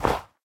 Sound / Minecraft / dig / snow3.ogg
snow3.ogg